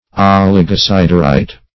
Search Result for " oligosiderite" : The Collaborative International Dictionary of English v.0.48: Oligosiderite \Ol`i*go*sid"er*ite\, n. [Oligo- + siderite.]
oligosiderite.mp3